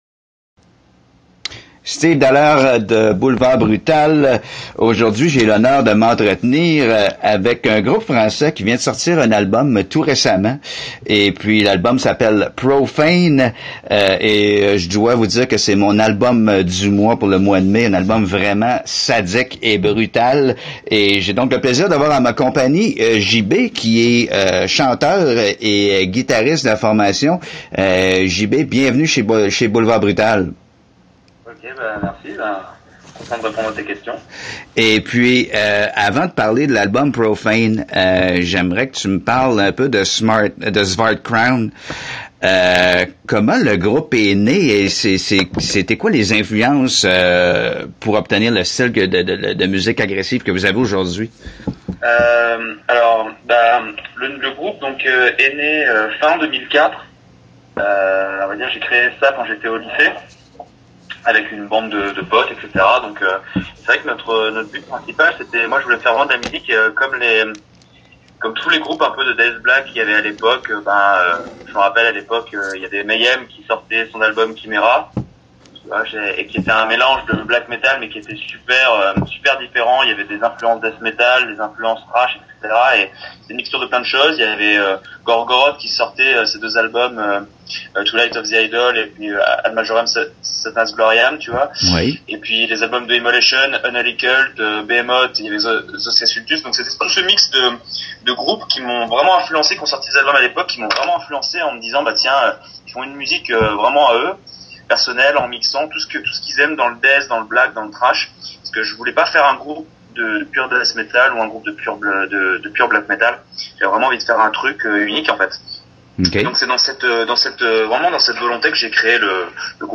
entrevue-svart-crown.mp3